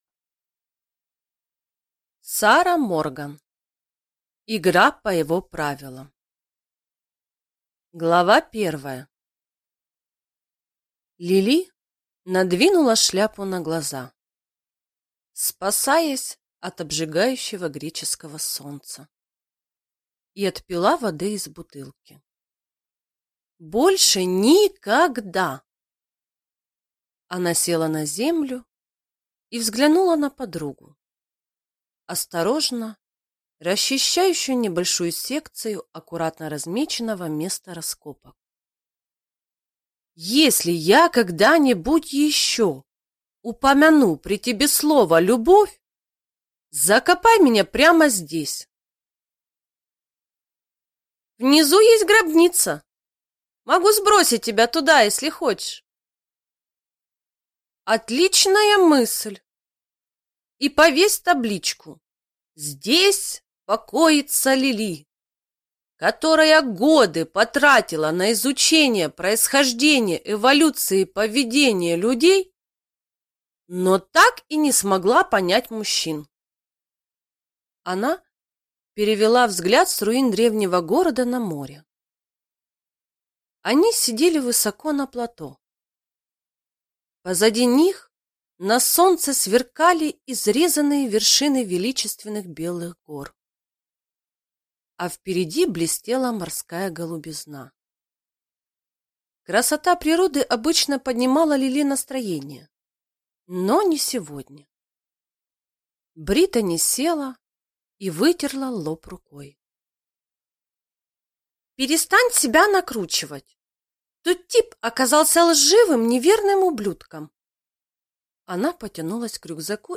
Аудиокнига Игра по его правилам | Библиотека аудиокниг